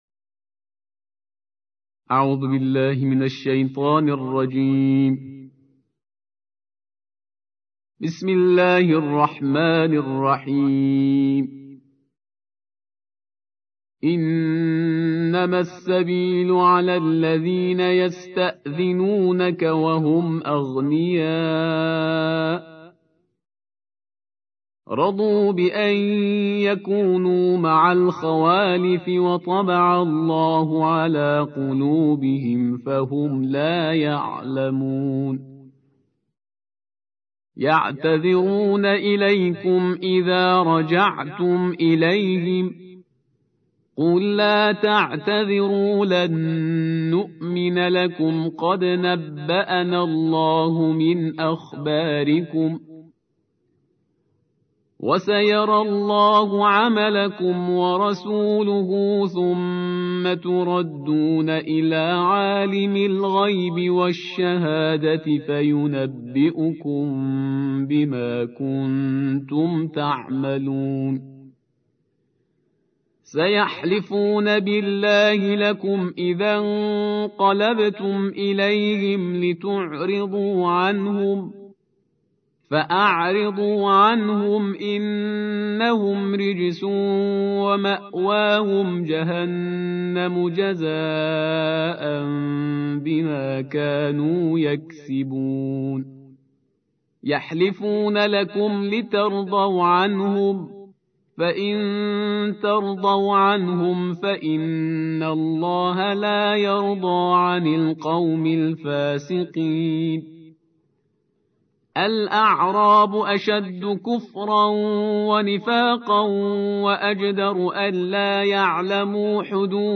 تحميل : الجزء الحادي عشر / القارئ شهريار برهيزكار / القرآن الكريم / موقع يا حسين